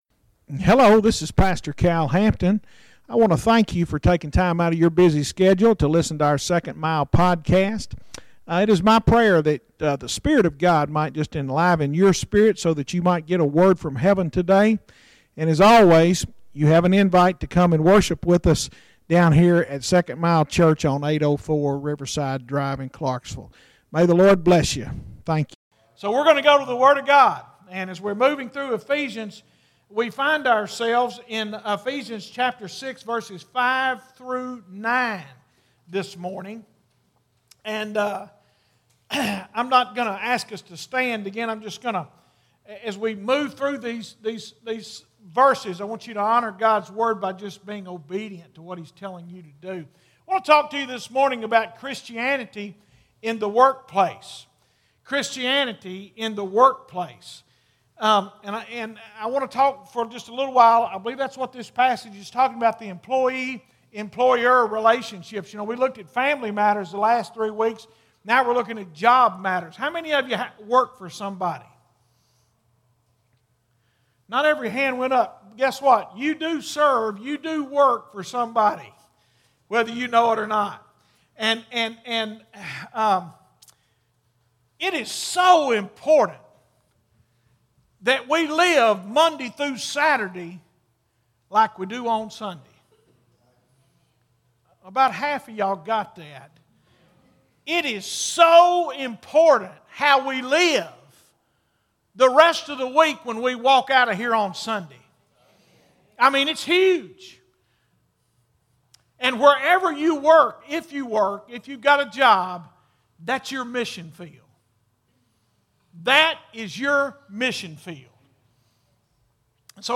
2021 Revival Testimonials Heartfelt testimonies from congregation.